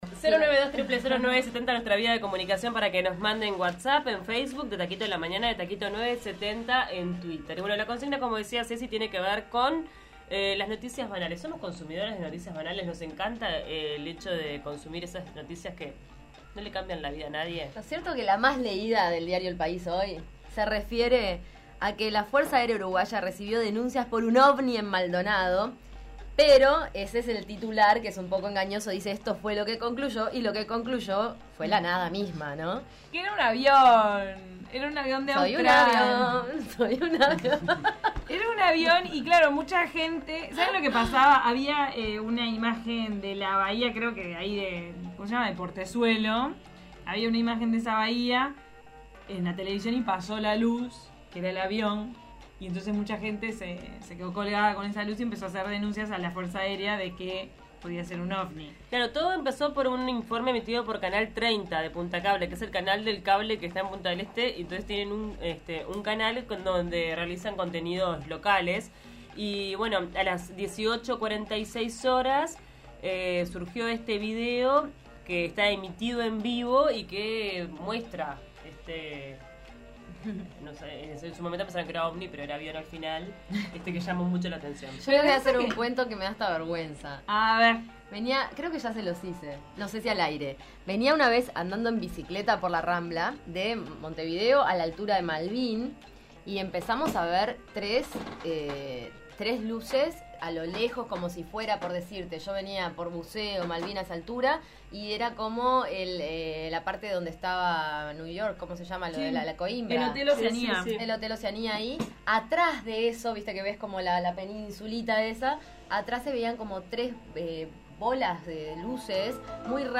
La mayoría de los transeúntes que pasaban por 18 de julio recordaron noticias vinculadas a casos de abuso sexual y crímenes.